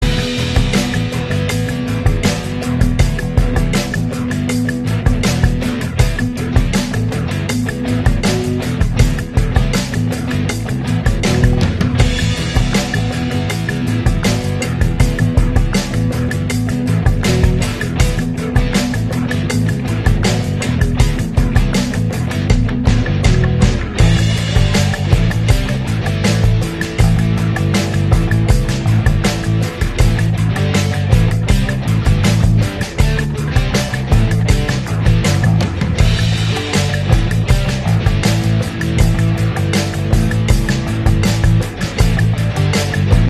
Quita el pitido del ups sound effects free download